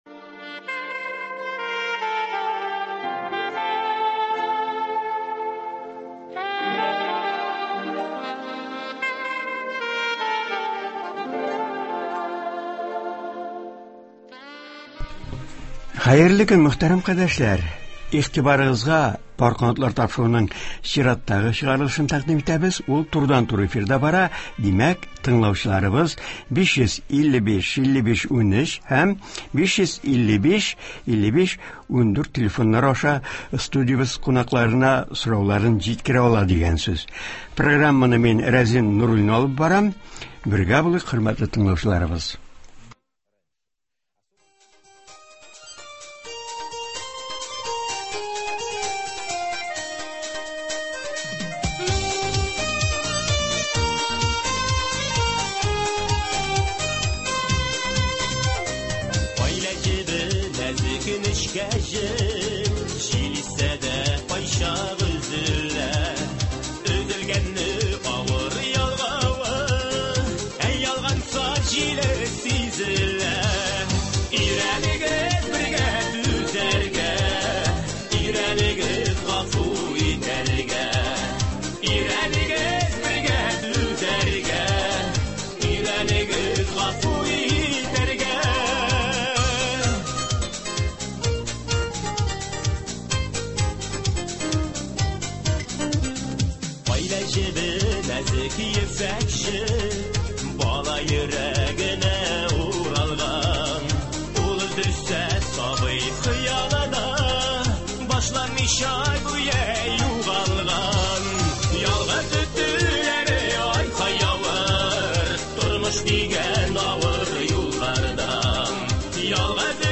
Турыдан-туры элемтә тапшыруында хәзрәт үзе катнашып, тыңлаучыларны кызыксындырган сорауларга җавап бирәчәк.